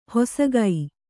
♪ hosagai